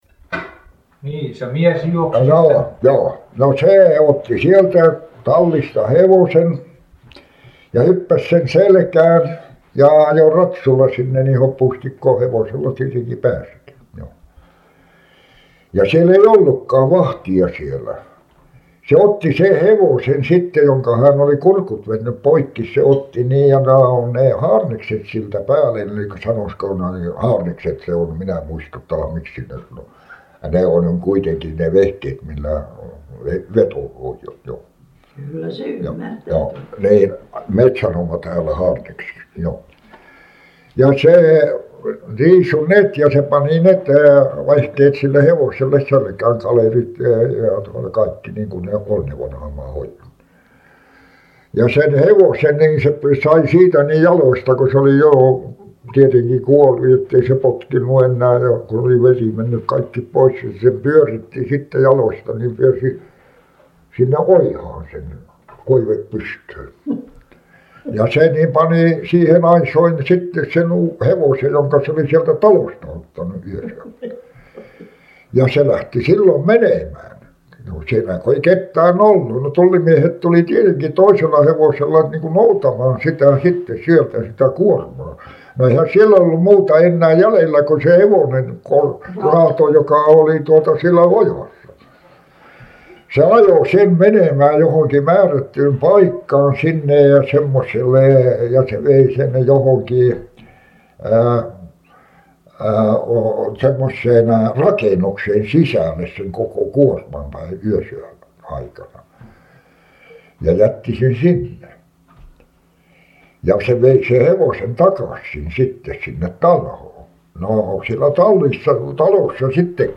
Inspelningarna innehåller huvudsakligen fritt tal av så kallade informanter, och berättelser om ämnen som de känner till, minnen och erfarenheter.
Inspelningarna gjordes från och med slutet av 1950-talet under intervjuresor med arkivets personal och insamlare som fått finansiering.